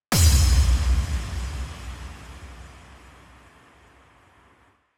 SouthSide Stomp (4) .wav